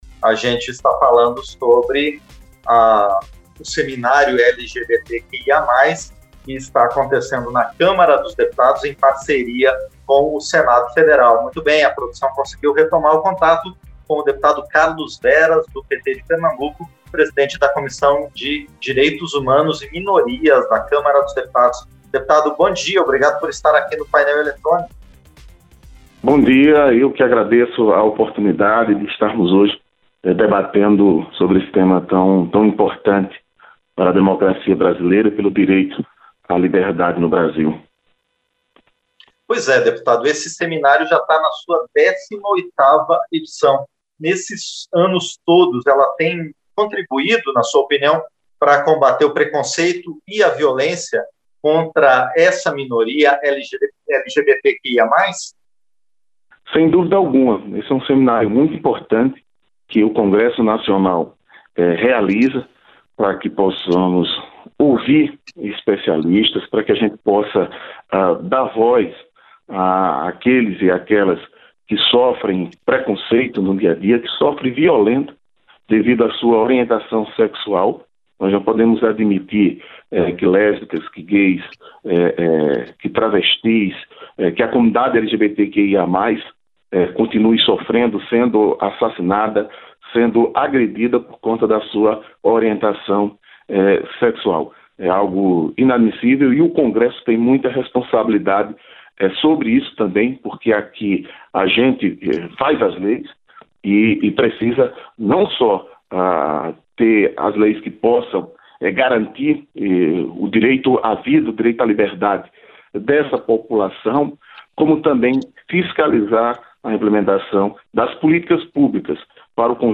Entrevista - Dep. Carlos Veras (PT-PE)